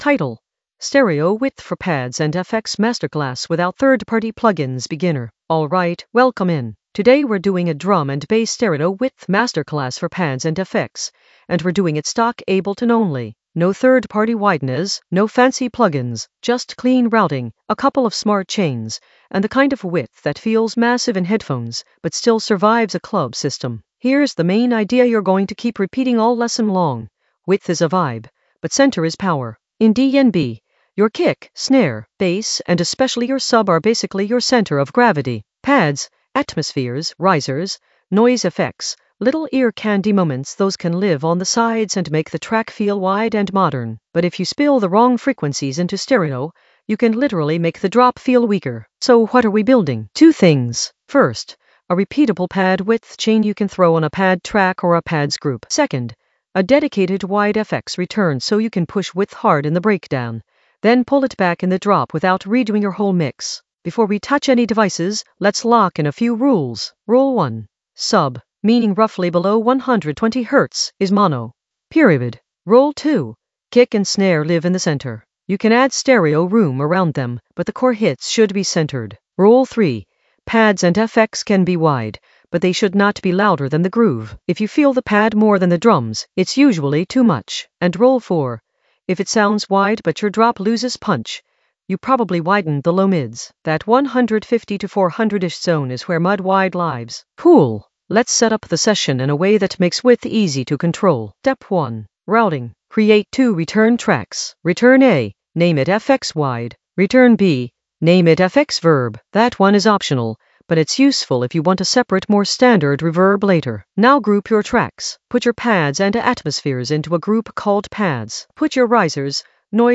Narrated lesson audio
The voice track includes the tutorial plus extra teacher commentary.
stereo-width-for-pads-and-fx-masterclass-without-third-party-plugins-beginner-mixing.mp3